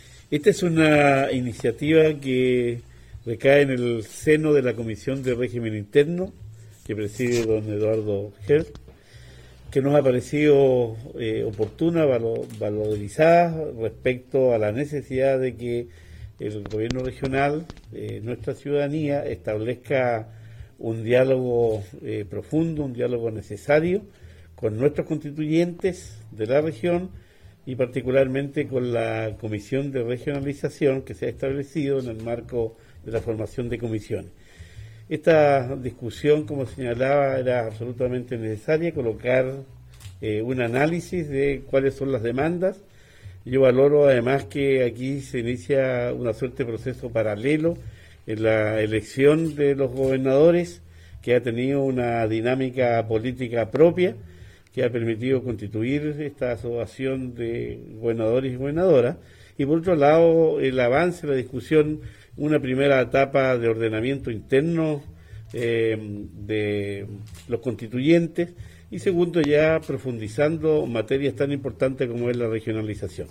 El Consejo Regional de Los Ríos realizó el seminario titulado “La Descentralización en el Marco de la Nueva Constitución”, en dependencias del auditorio del edificio de la PDI, en Valdivia.
Gobernador-Luis-Cuvertino-Seminario.mp3